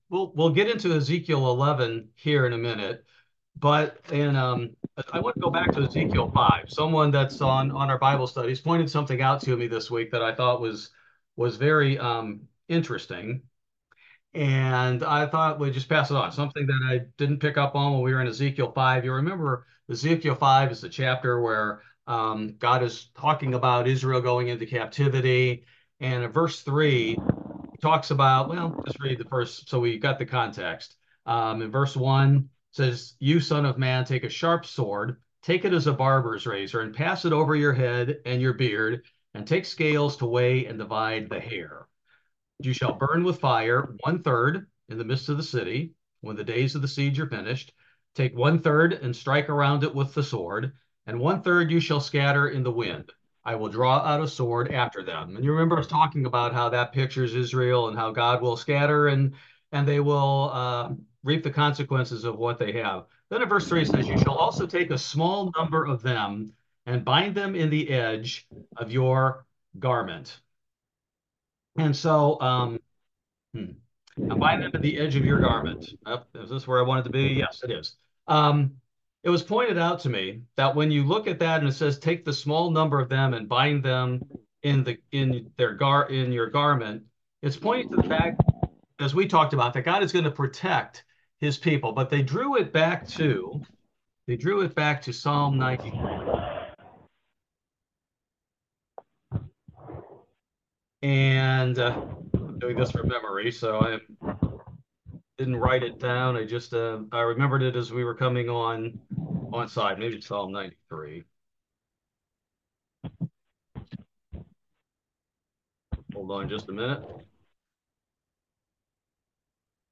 Bible Study: June 12, 2024